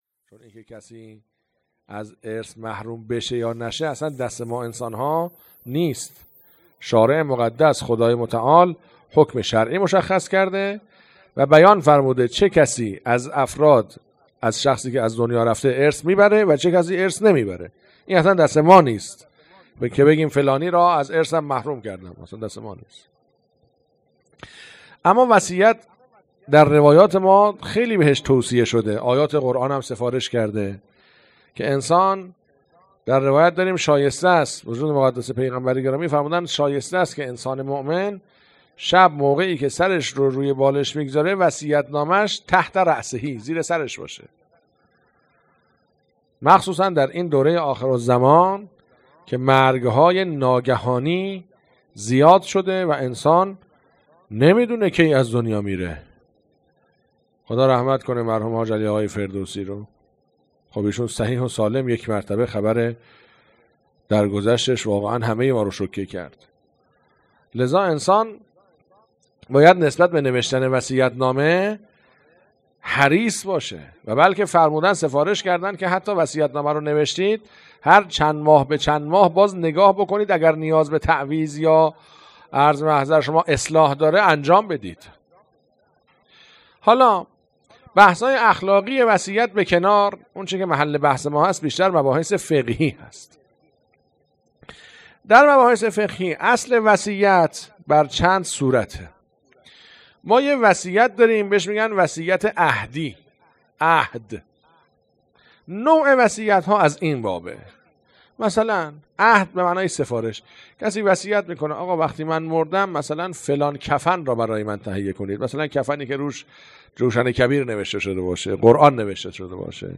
هیئت عقیله بنی هاشم سبزوار
سلسله جلسات احکام